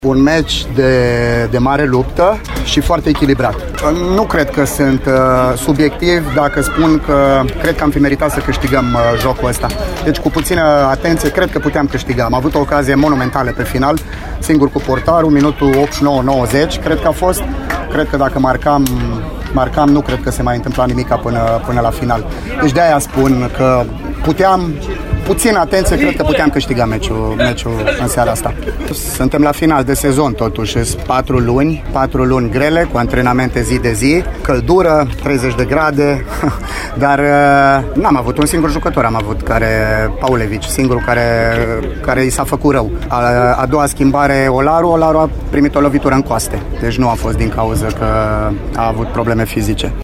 La declaraţiile de după meci